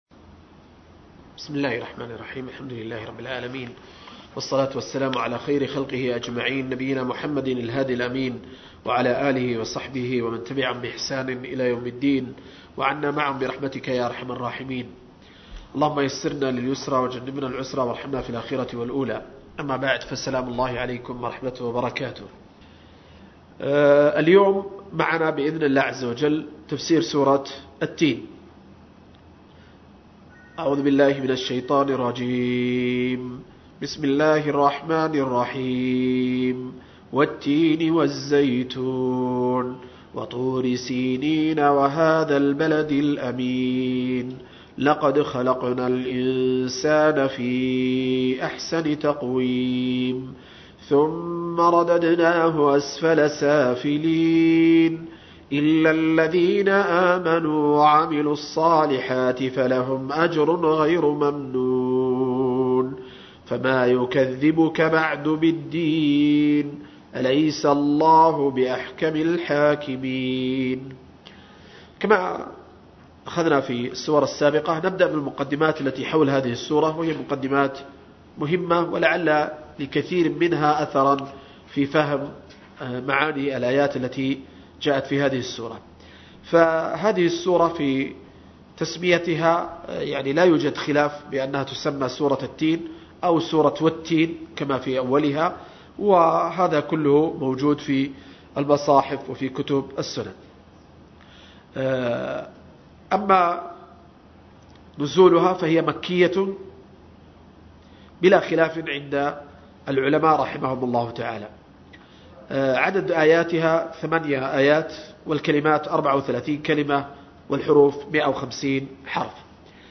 11-التفسير الموضوعي الميسر لقصار المفصل – الدرس الحادي عشر